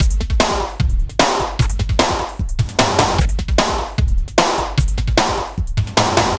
CRATE HC DRM 2.wav